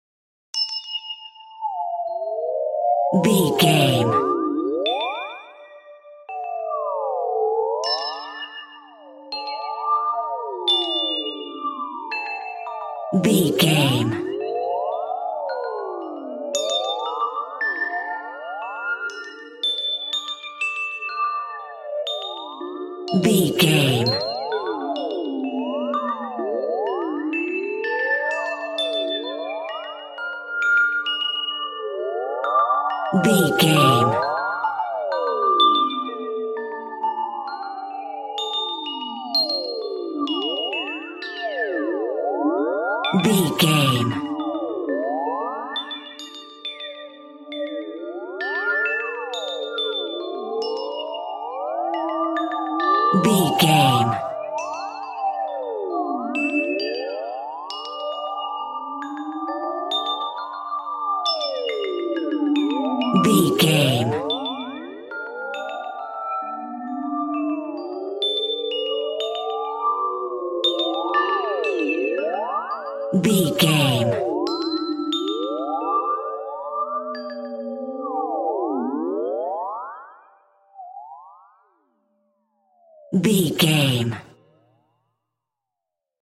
Space Sounds that are Scary.
Sound Effects
Atonal
ominous
eerie
Horror synth
Horror Ambience
electronics
synthesizer